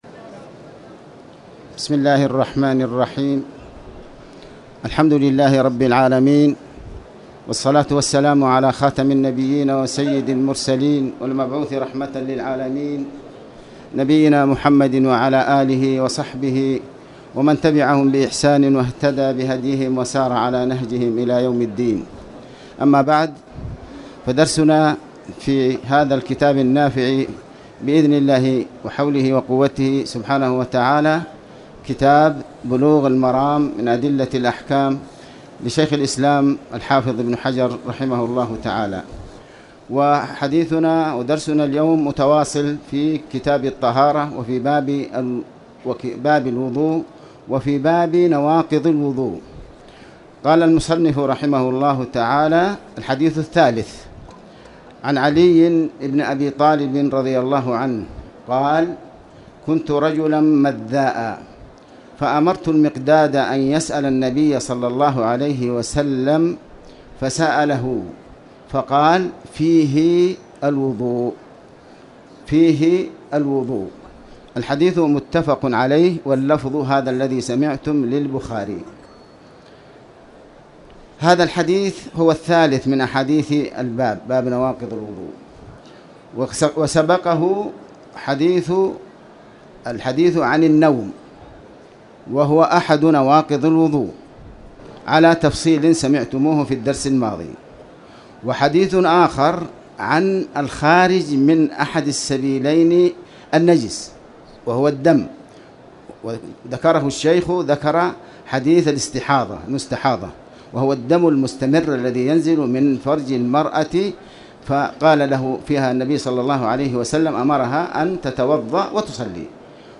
تاريخ النشر ٢٤ جمادى الآخرة ١٤٣٨ هـ المكان: المسجد الحرام الشيخ